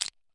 苏打水 " 苏打水 罐装 脆皮 05
描述：我手里拿着一罐空罐子。 用Tascam DR40录制。
Tag: 铝即可 嘎吱作响 苏打 罐头 饮料 苏打罐 挤压 金属 流行 粉碎 金属 饮料 食品 破碎 易拉罐 紧缩 嘎吱嘎吱